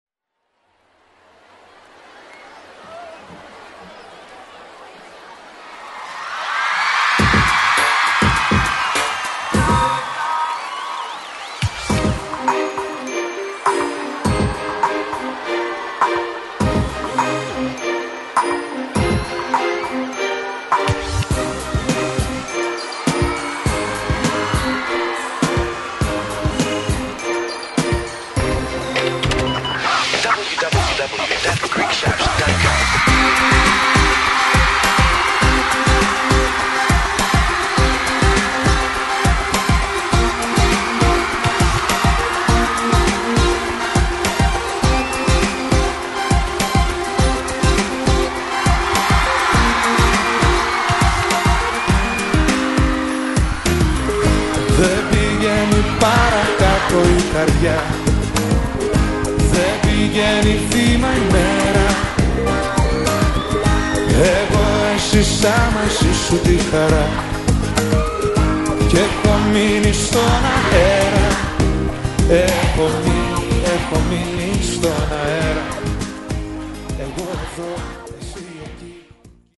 releases his second live album